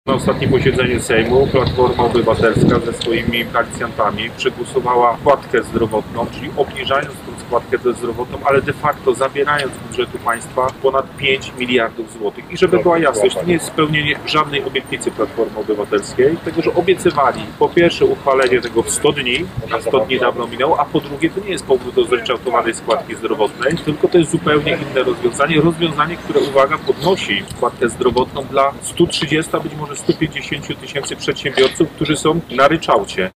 Kolejną w ostatnim czasie konferencję prasową zorganizowali dzisiaj działacze Prawa i Sprawiedliwości z okręgu szczecińskiego, podczas której poruszono kluczowe tematy dotyczące służby zdrowia, w tym obniżenia składki zdrowotnej.